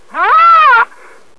c_rhino_dead.wav